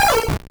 Cri de Canarticho dans Pokémon Rouge et Bleu.